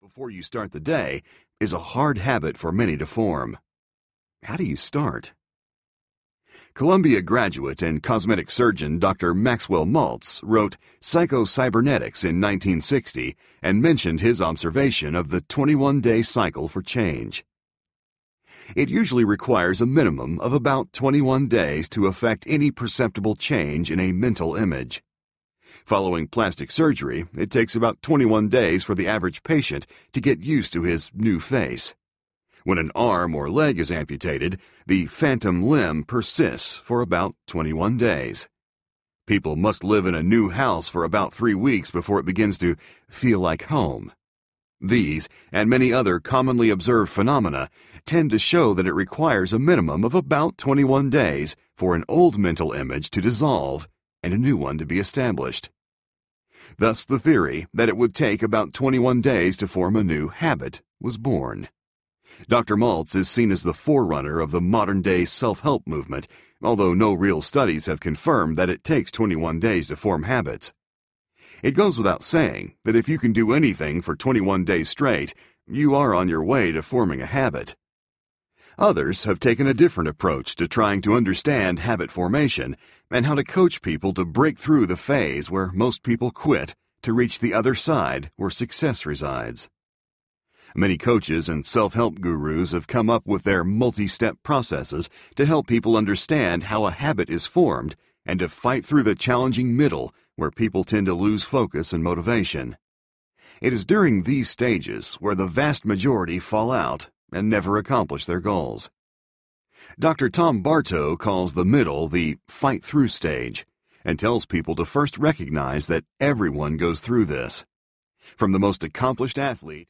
The Leap Audiobook
Narrator
5.67 Hrs. – Unabridged